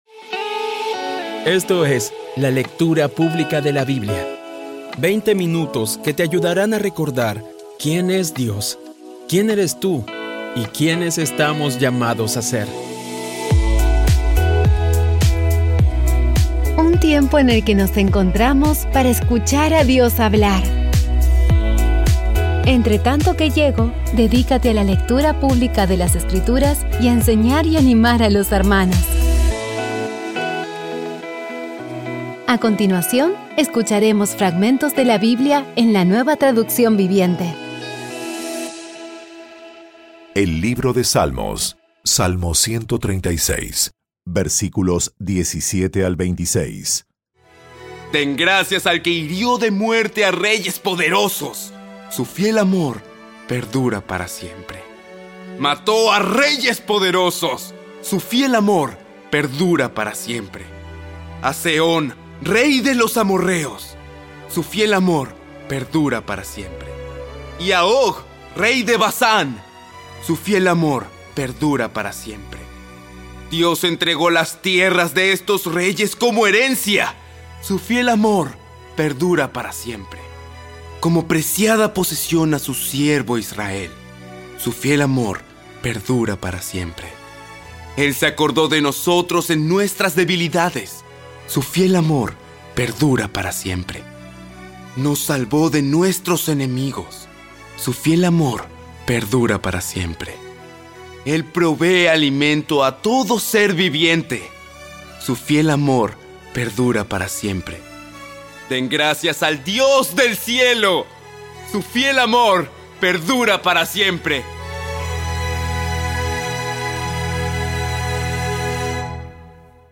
Audio Biblia Dramatizada Episodio 339
Poco a poco y con las maravillosas voces actuadas de los protagonistas vas degustando las palabras de esa guía que Dios nos dio.